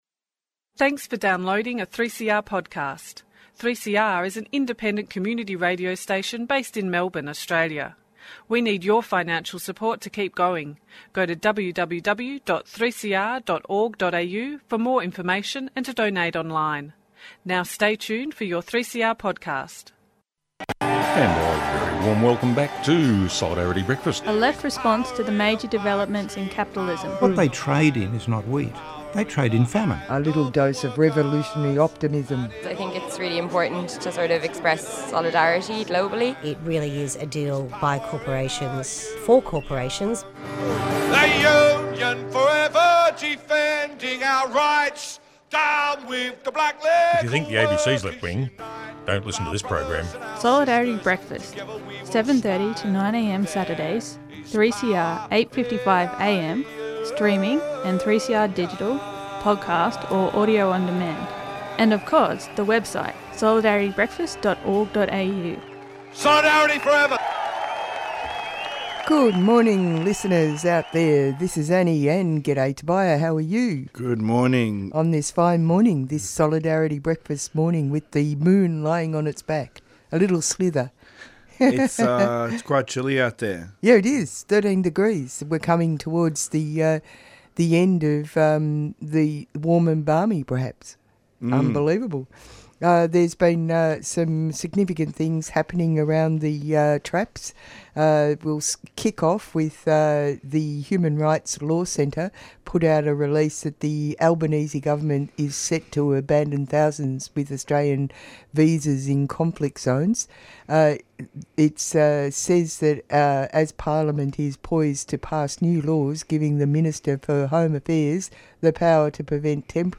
This is from a Jesuit Social Serices - Eco-justice hub forum: